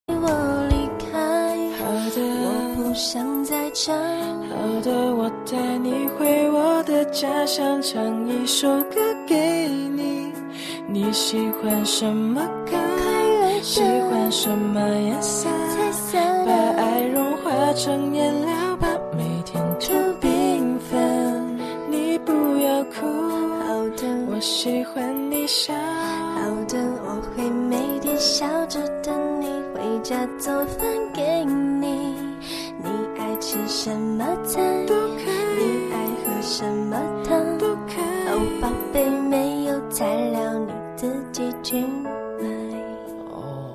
M4R铃声, MP3铃声, 华语歌曲 113 首发日期：2018-05-15 20:10 星期二